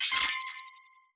Special & Powerup (9).wav